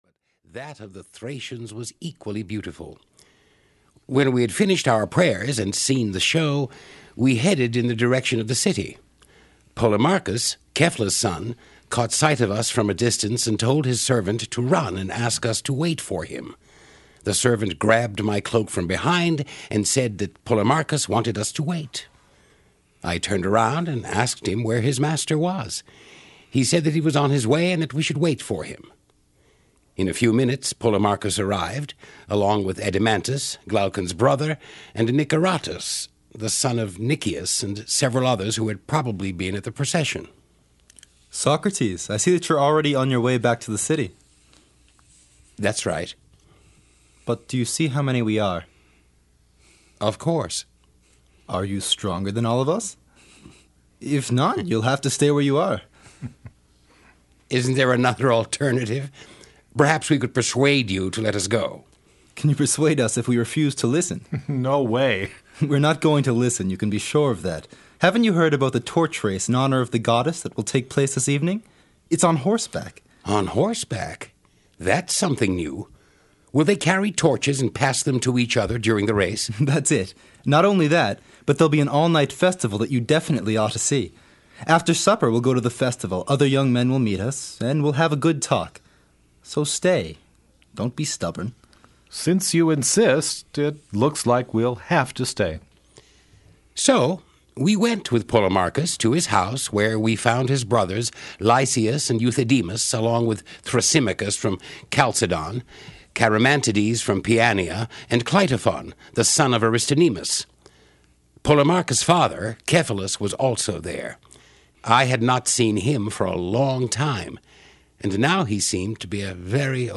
Plato’s Republic (EN) audiokniha
Ukázka z knihy